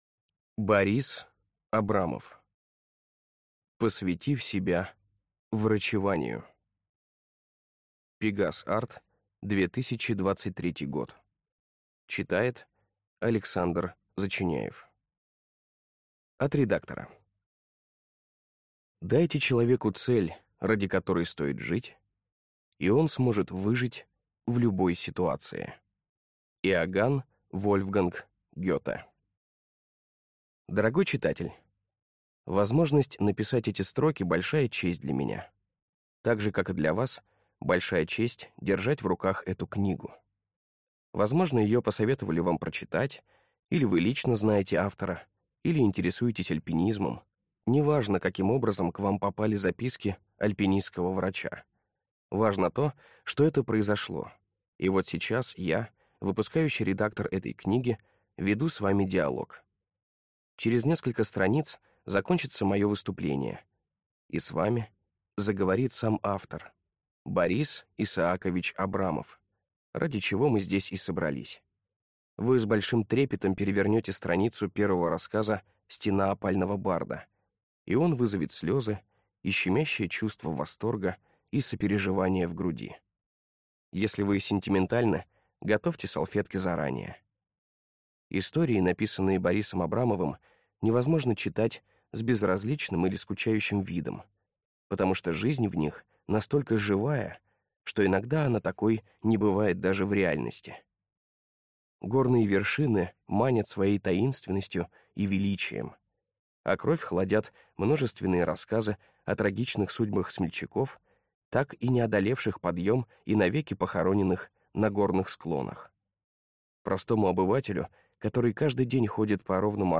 Аудиокнига Посвятив себя врачеванию | Библиотека аудиокниг